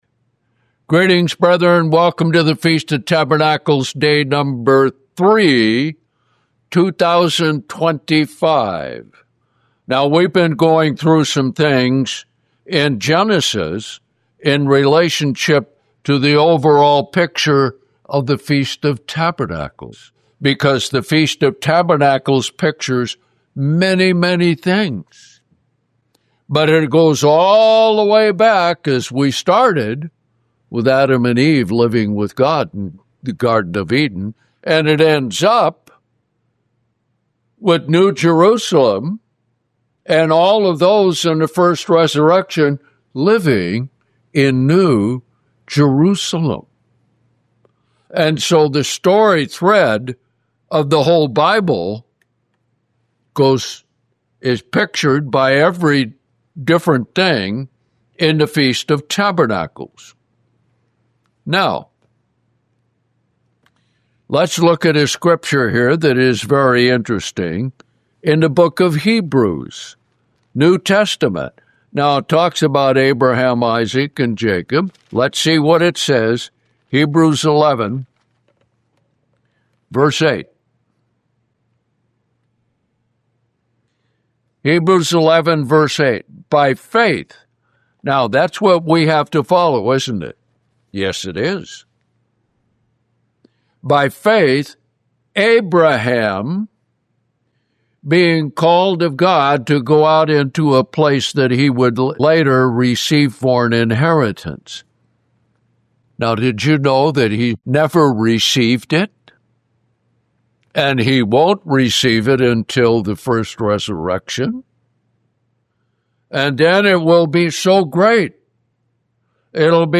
(FOT - Day 3)